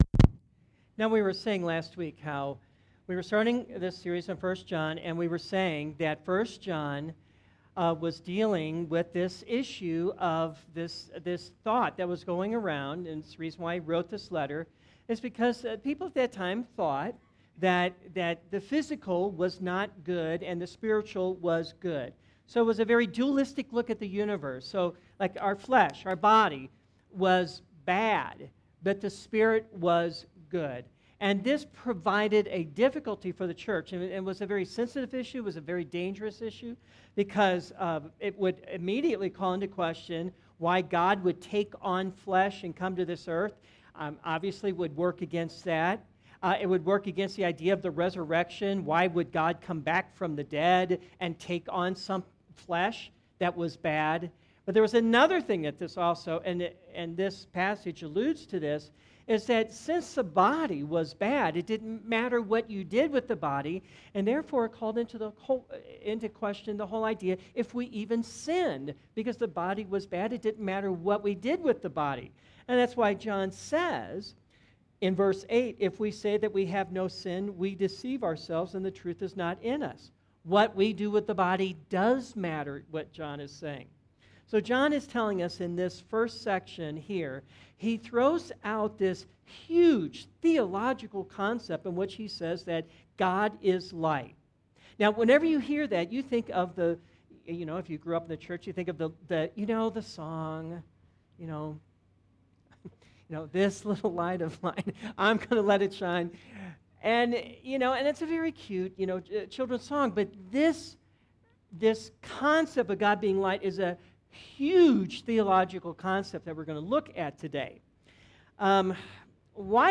Sermons - Redeemer Presbyterian Church